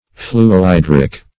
Fluohydric \Flu`o*hy"dric\